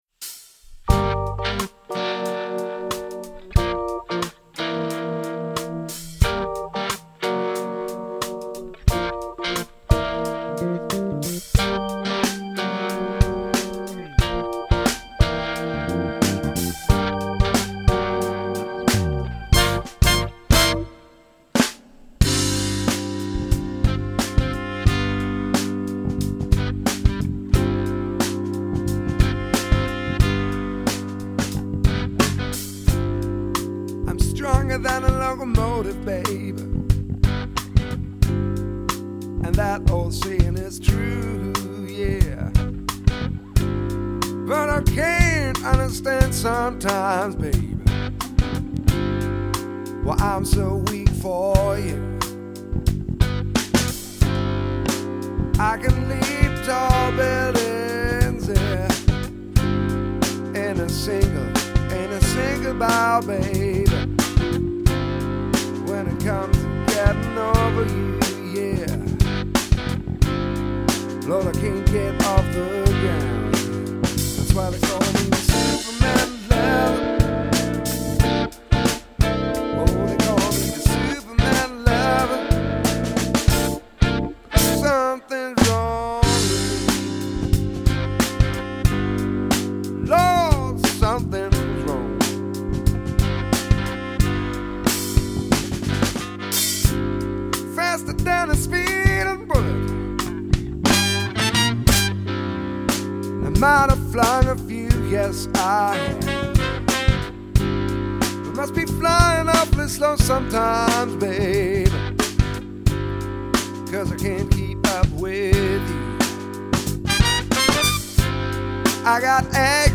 100 % Live Musik!